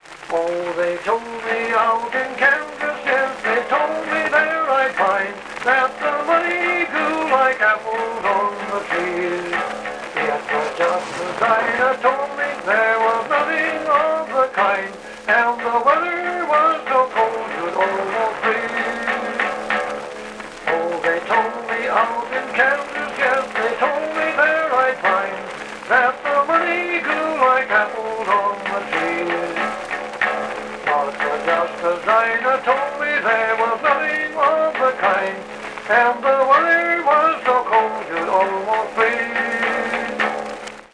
California Gold: Northern California Folk Music from the Thirties, Library of Congress.